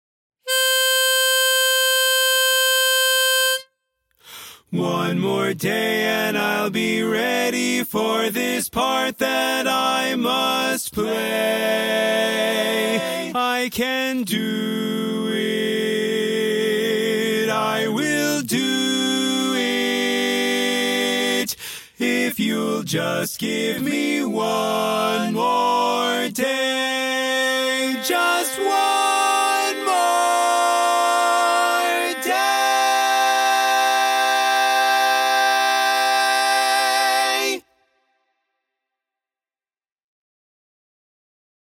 Key written in: C Major
Type: Barbershop